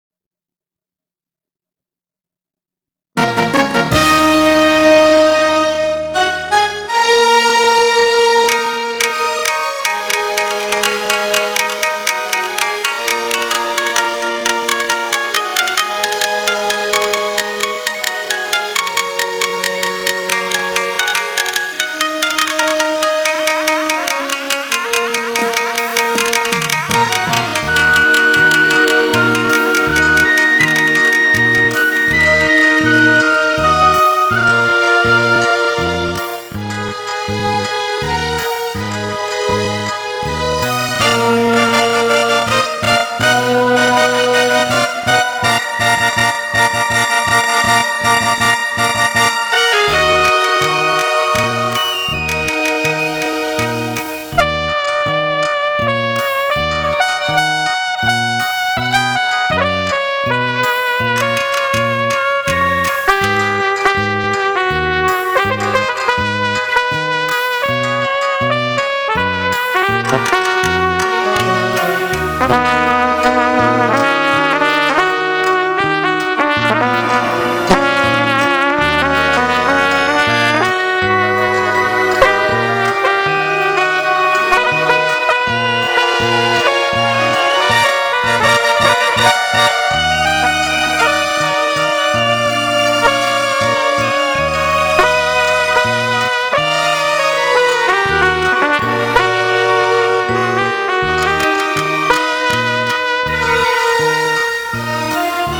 高音部キーのみ変えている「カラオケ」がある
参考：ちなみに、カラオケを一オクターブ上げると